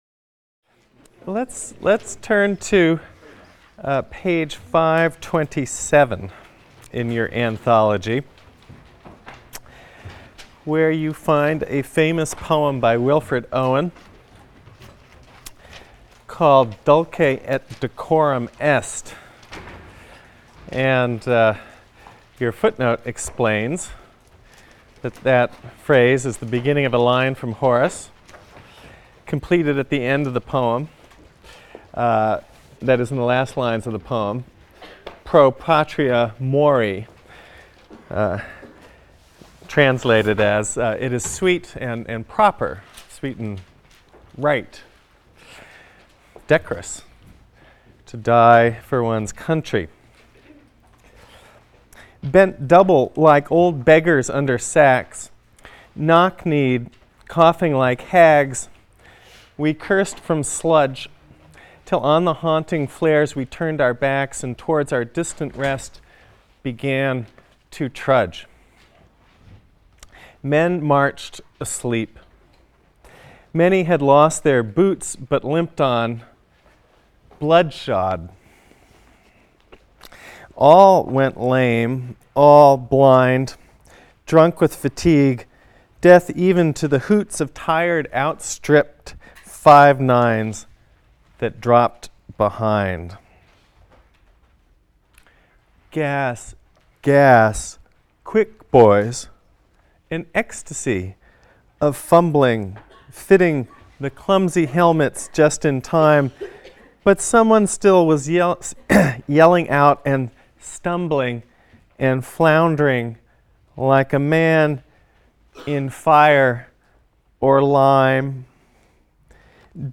ENGL 310 - Lecture 7 - World War I Poetry in England | Open Yale Courses